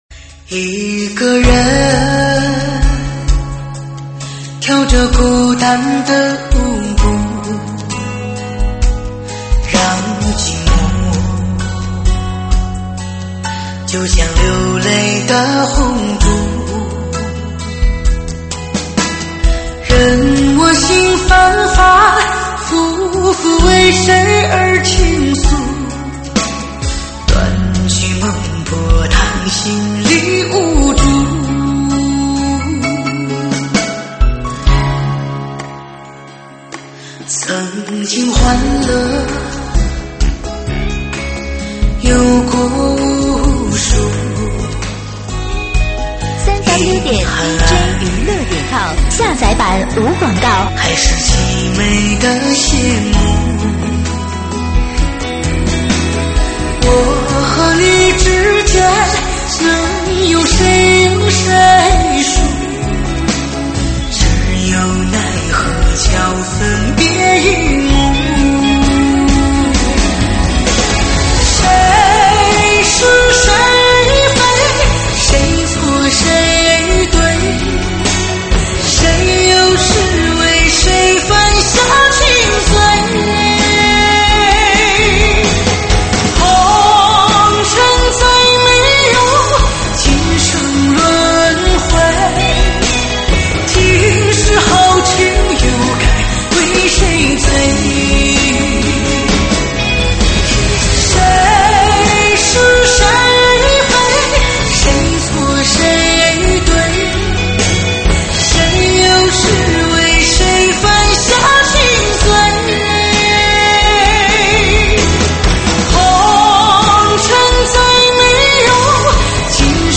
舞曲编号：81383